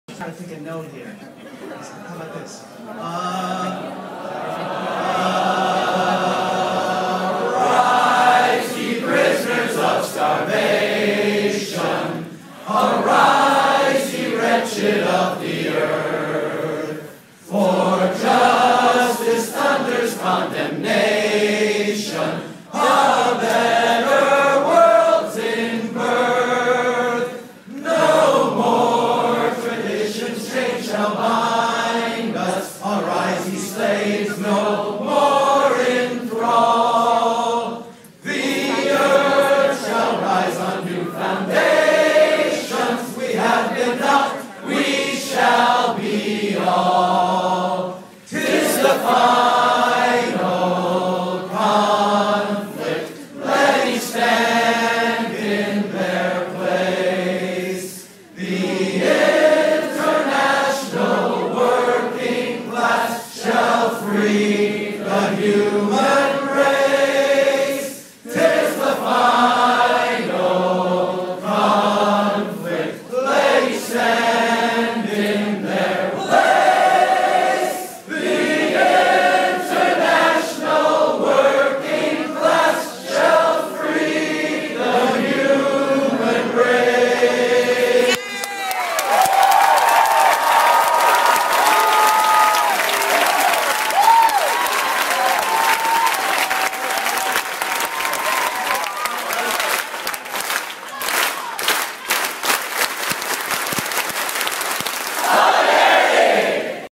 "The Internationale" audio, sung at the Socialism 2013 Conference in Chicago